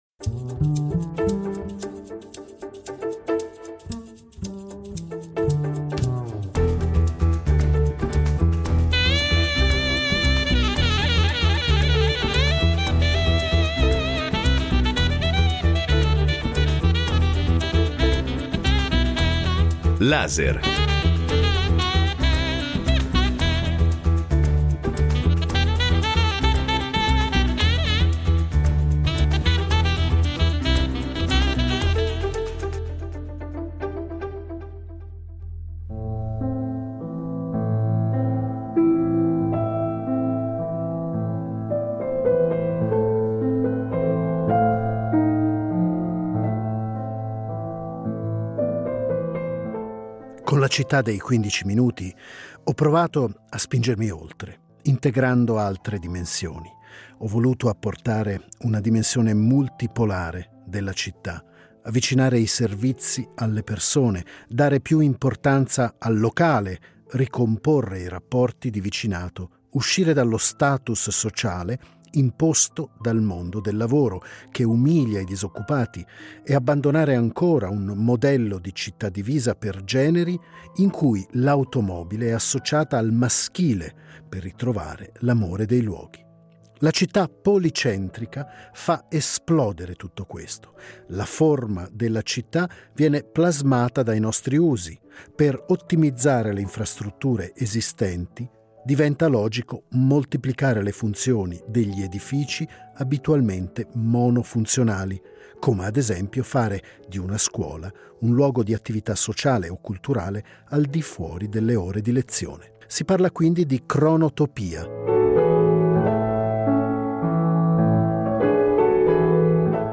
Incontro con l’urbanista Carlos Moreno